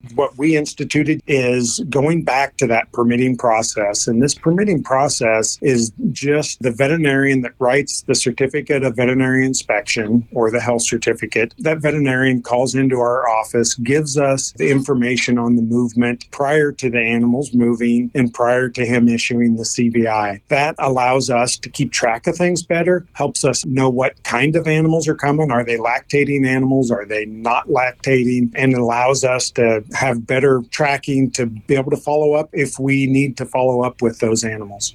The NDA has issued restrictions on dairy cattle entering the state. Roger Dudley, the Nebraska State Veterinarian, talks about the requirements:34 OC…”those animals.”
Audio with Roger Dudley, Nebraska State Veterinarian